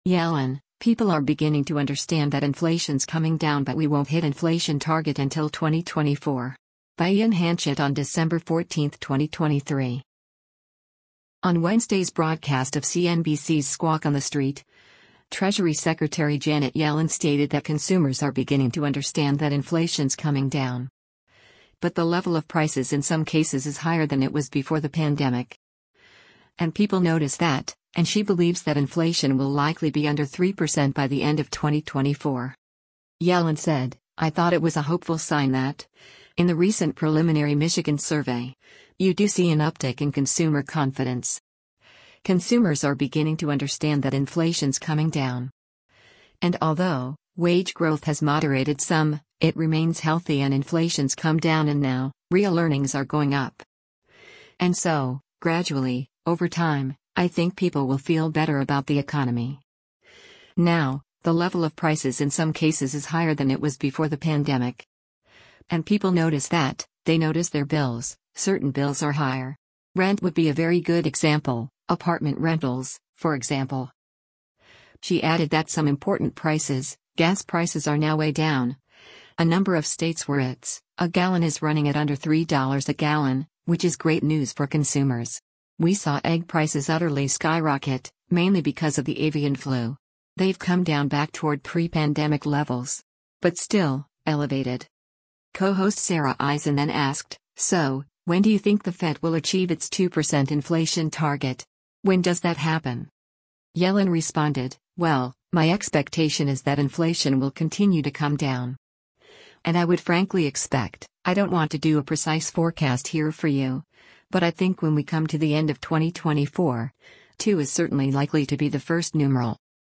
On Wednesday’s broadcast of CNBC’s “Squawk on the Street,” Treasury Secretary Janet Yellen stated that “Consumers are beginning to understand that inflation’s coming down.” But “the level of prices in some cases is higher than it was before the pandemic. And people notice that,” and she believes that inflation will likely be under 3% by the end of 2024.
Co-host Sara Eisen then asked, “So, when do you think the Fed will achieve its 2% inflation target? When does that happen?”